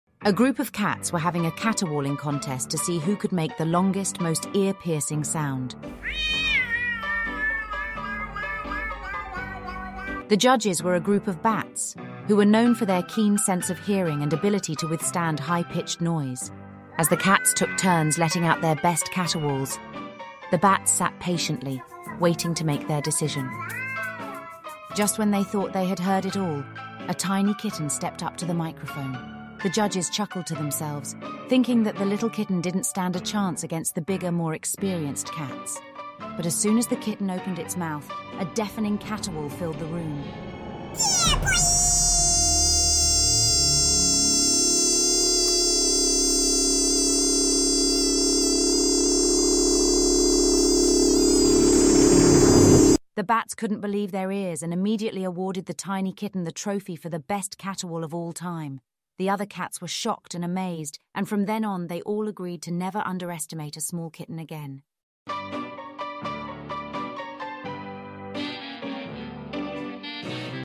cats having a caterwauling competition sound effects free download